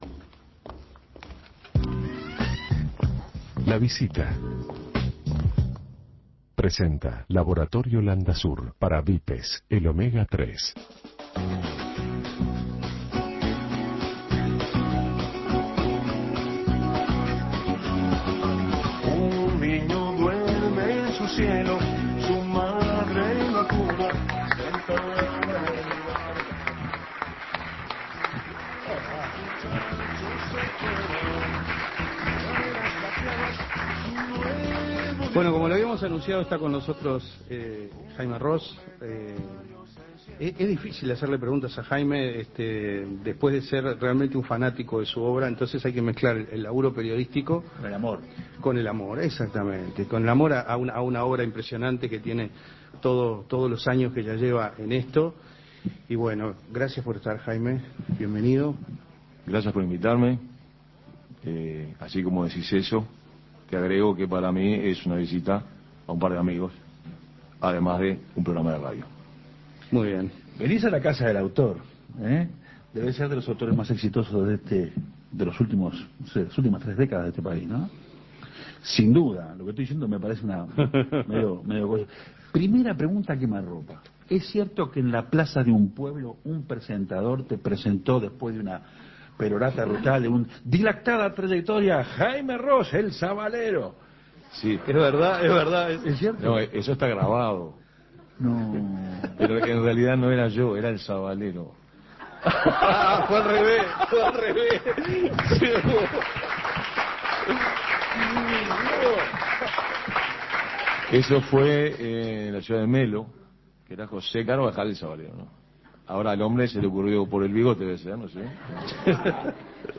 Antes de sus presentaciones en Moviecenter, desde Agadu, el músico y cantautor Jaime Roos compartió una charla entre amigos en Otra Historia, recordando sus raíces en el bajo, su llegada a la guitarra, el usar la voz. Un paseo por sus orígenes e influencias para construir el éxito que perdura tras tantos años sobre el escenario.